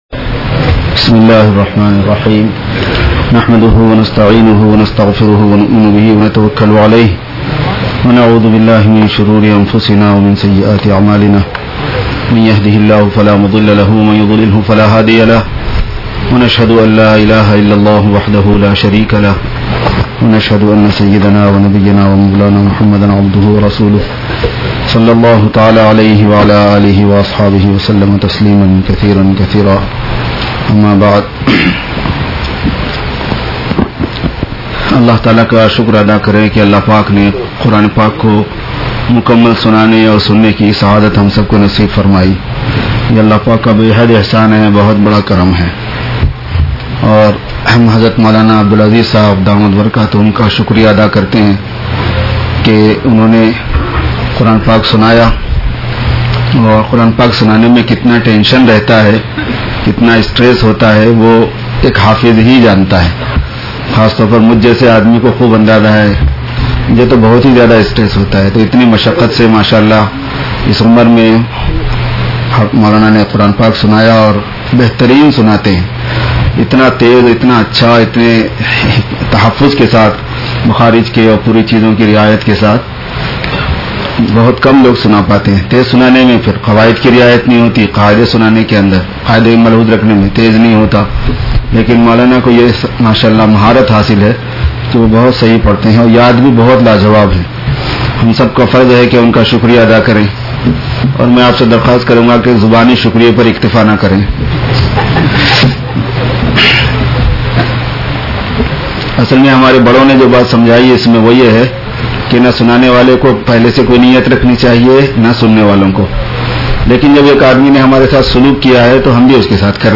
Dars-e-Qur'an